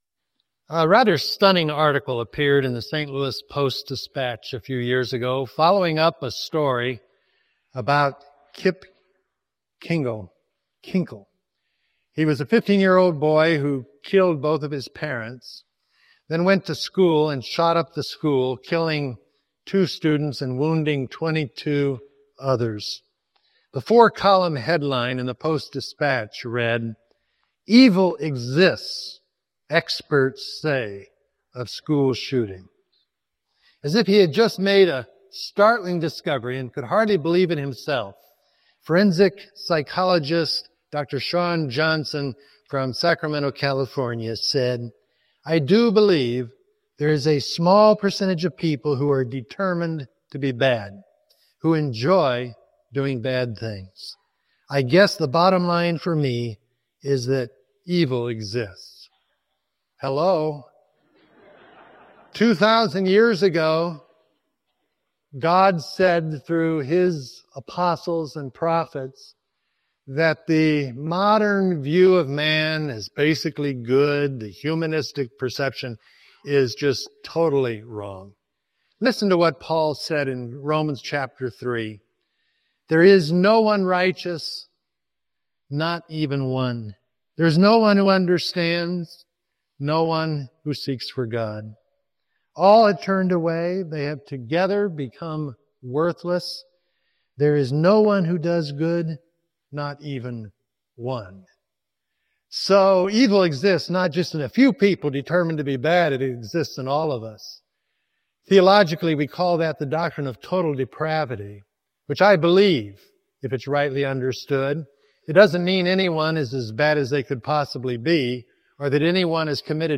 I hope that doesn’t disillusion you and cause you to leave this morning before you even hear the sermon. But there are hard-core antagonists in the church who work to undermine God’s work and God’s servants.